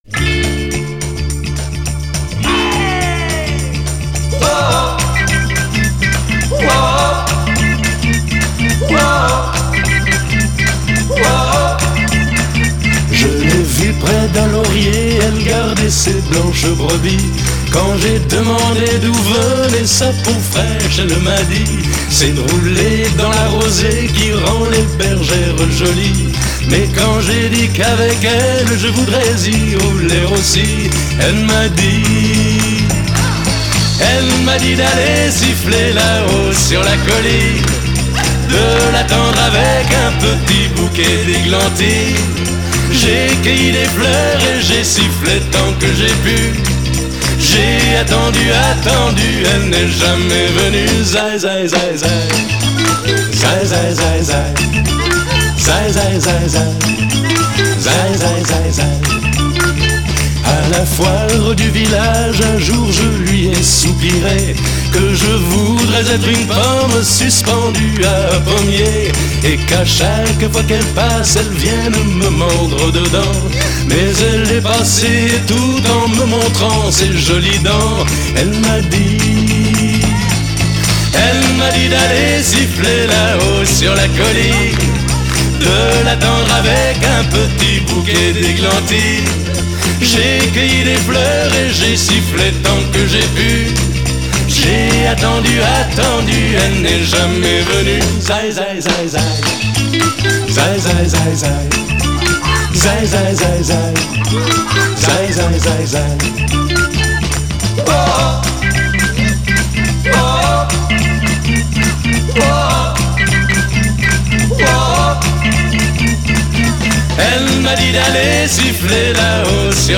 Chanson Française / Pop